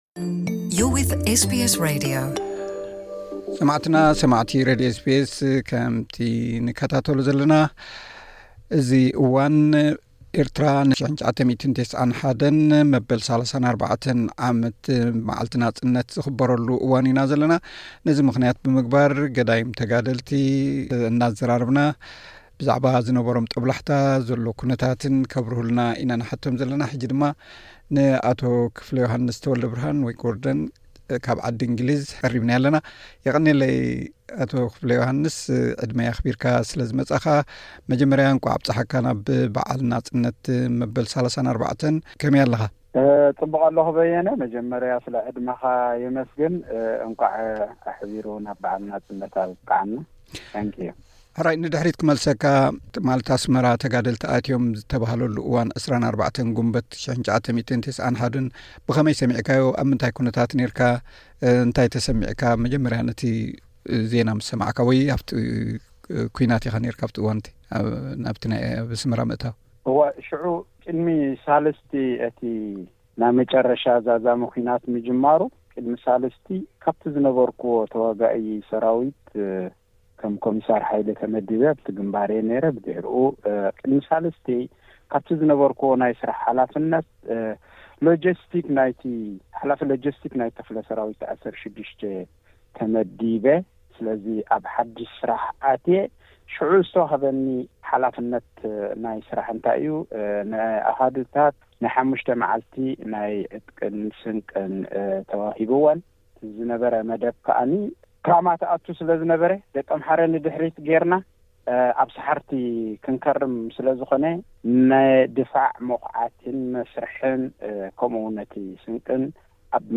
ዝተገብረ ሓጺር ዕላል።